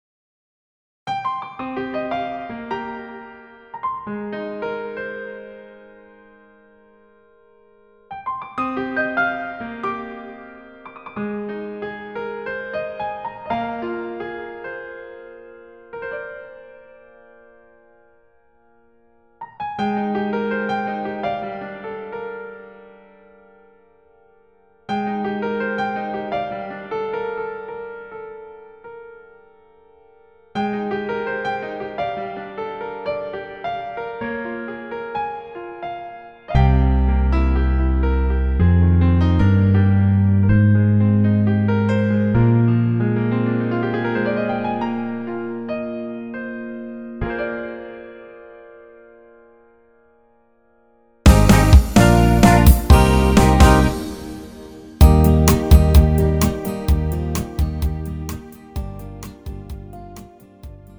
' (가요) MR 반주입니다.
Lite MR은 저렴한 가격에 간단한 연습이나 취미용으로 활용할 수 있는 가벼운 반주입니다.